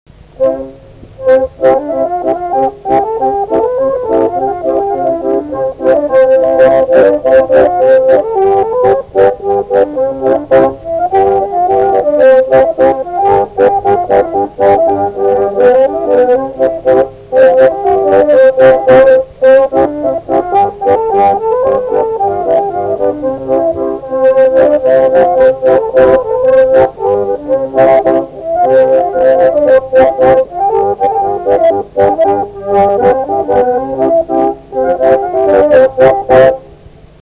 Dalykas, tema šokis
Erdvinė aprėptis Gripiškės
Atlikimo pubūdis instrumentinis
Instrumentas bajanas